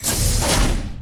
doorsopen.wav